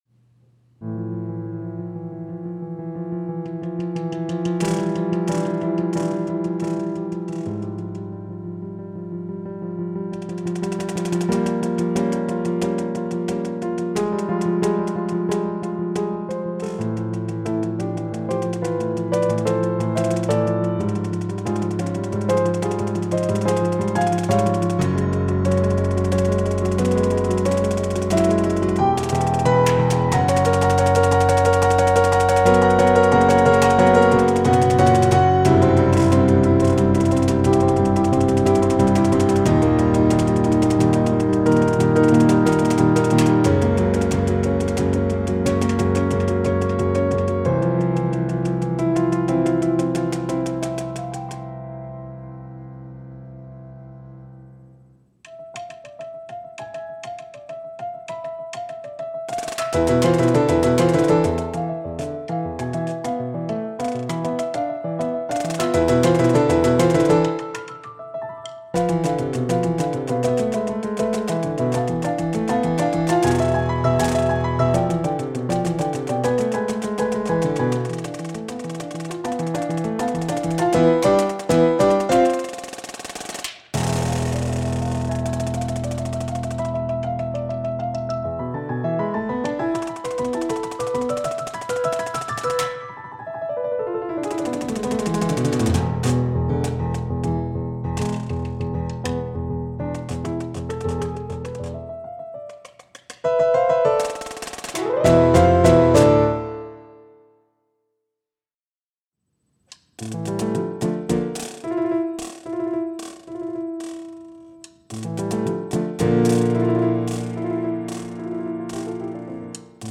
Voicing: Snare Drum